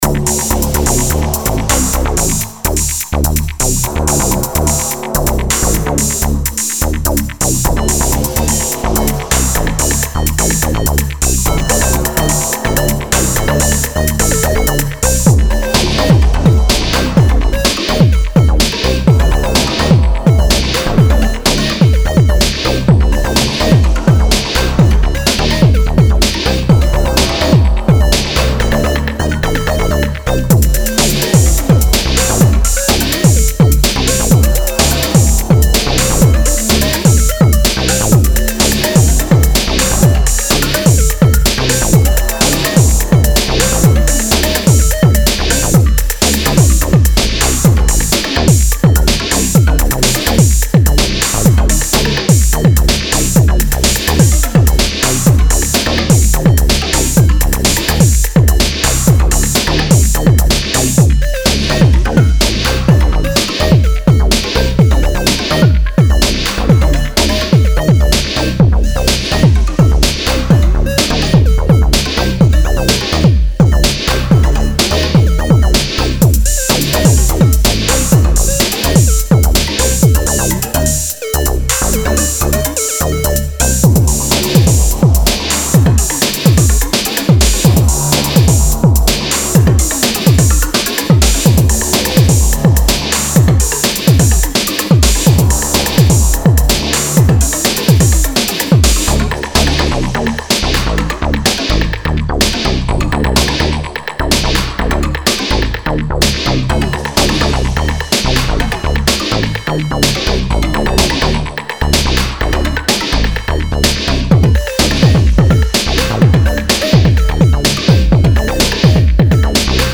前2作はアシッド・ハウスのフォーカスした印象でしたが、今回はエレクトロに寄せています。